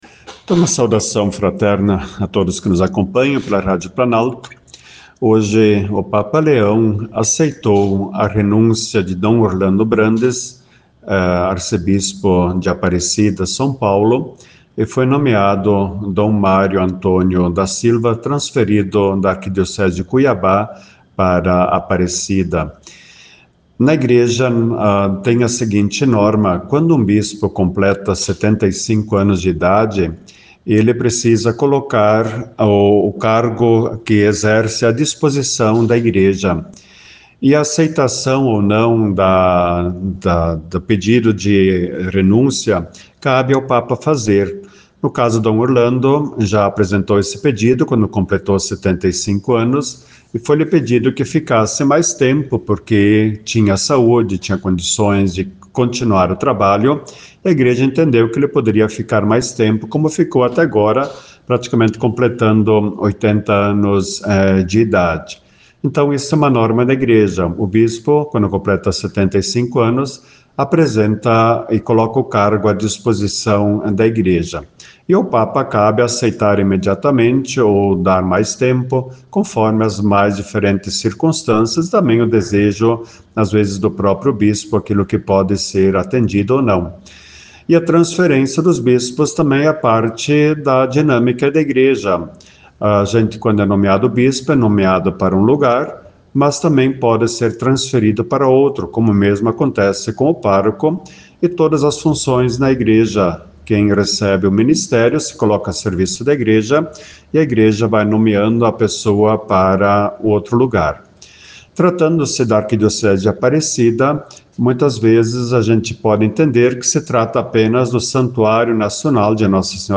O arcebispo de Passo Fundo, Dom Rodolfo Luís Weber, comentou na Rádio Planalto a decisão do Papa Leão XIV que aceitou a renúncia de Dom Orlando Brandes e nomeou Dom Mário Antônio da Silva como novo arcebispo de Aparecida, em São Paulo.